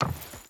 Wood Chain Run 3.wav